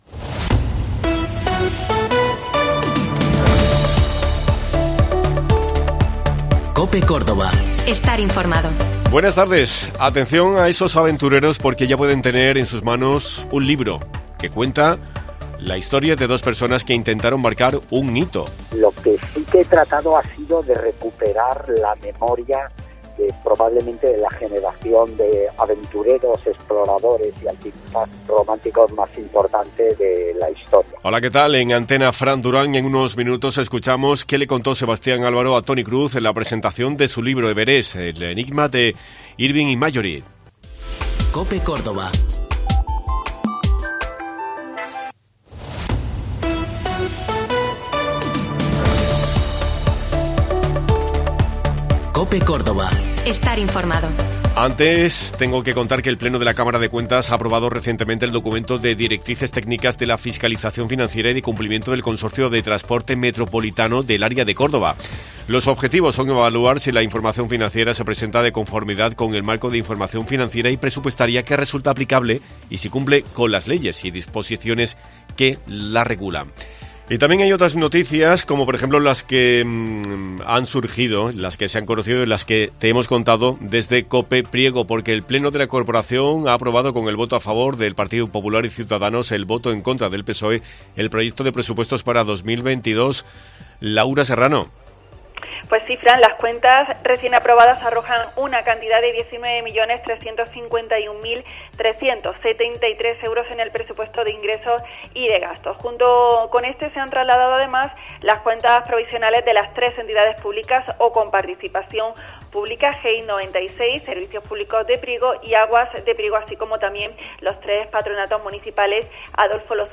Hoy hemos hablado con Sebastián Álvaro sobre su libro.